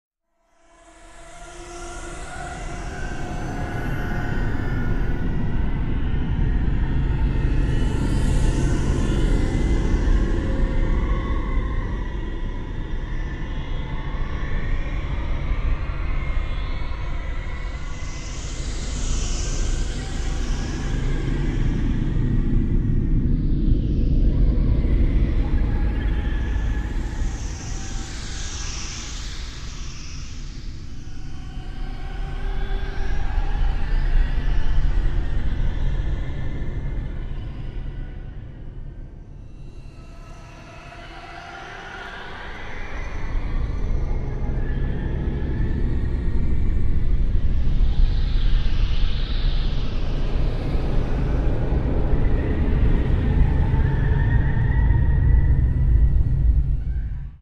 Scatter dark, hollow tones, with perpetual ominous sweeps of vocal distortion